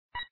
bullet_wall.ogg